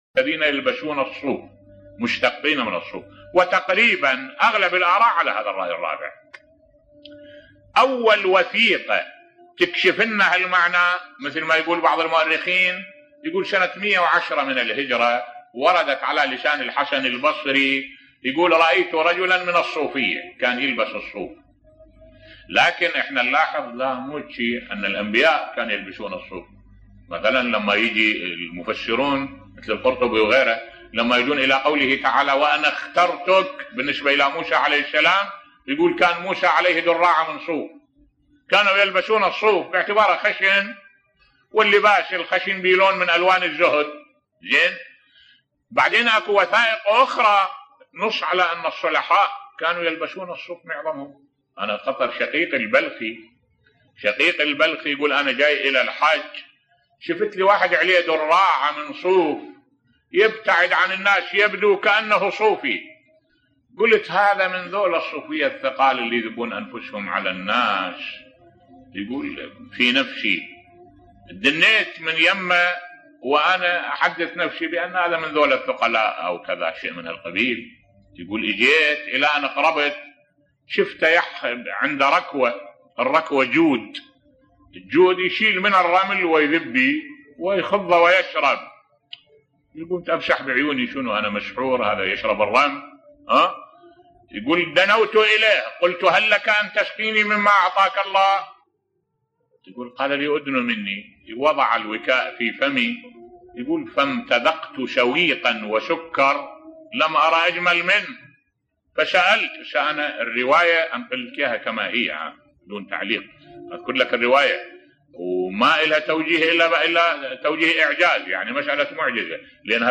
ملف صوتی كرامة للإمام موسى بن جعفر (ع) بصوت الشيخ الدكتور أحمد الوائلي